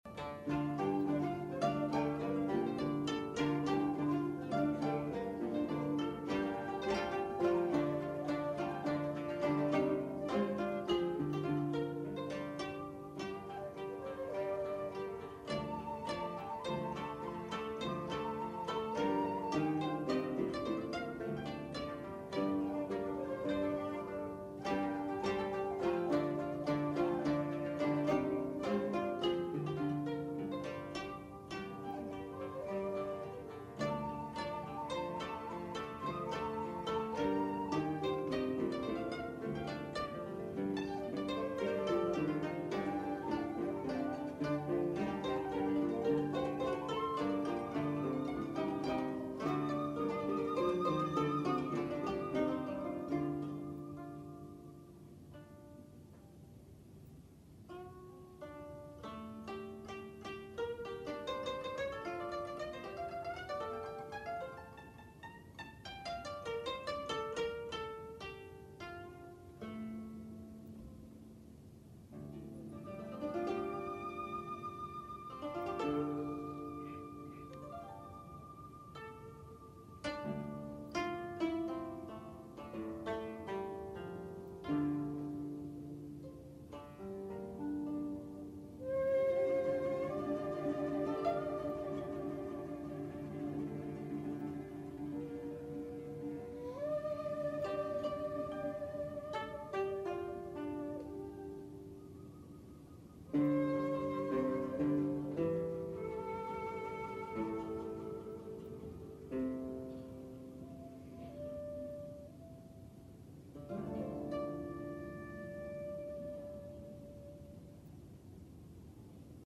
船川利夫作曲　　　　　　出雲路（テープの劣化が多少あります）
第一琴
第二琴
十七弦
尺八